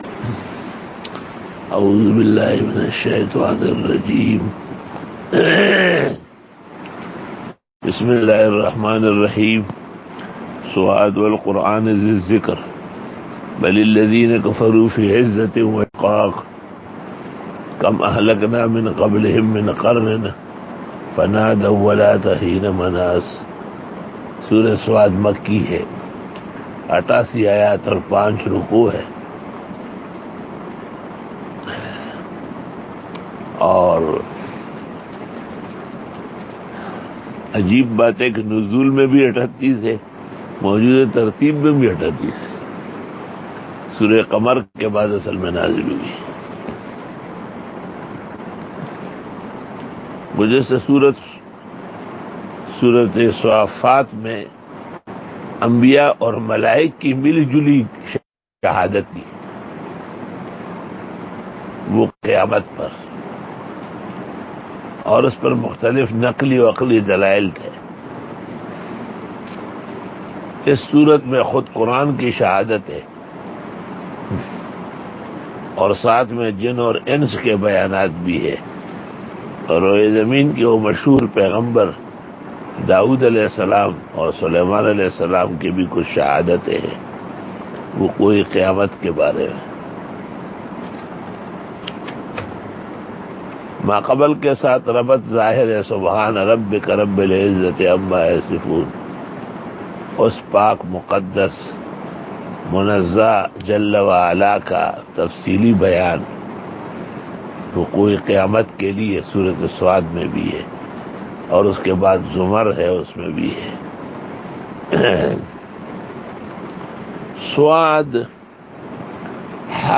57 Quran Tafseer 09 Jun 2020 (17 Shawwal 1441 H) Tuesday Day 57